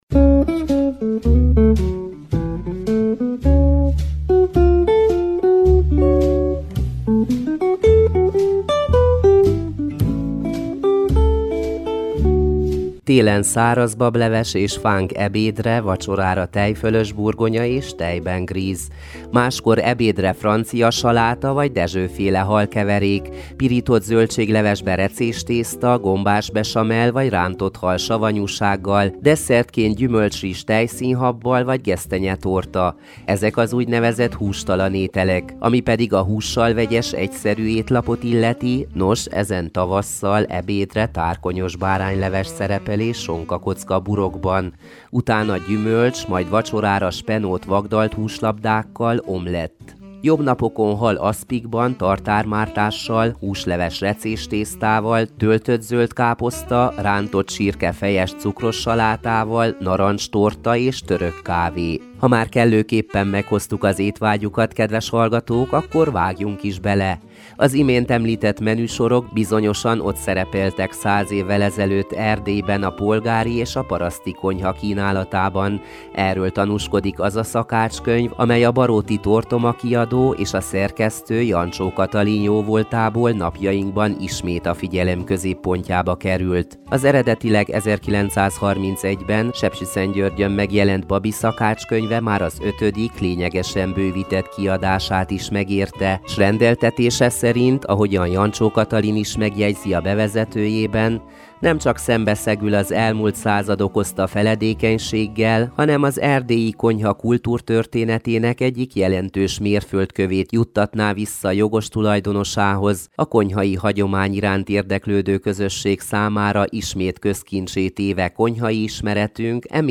Összeállításunkban, kollégáink tolmácsolásában különböző receptek is elhangzanak a könyvből, például a káposztalevesé, a miccsé, a narancslikőré és a jogásztortáé.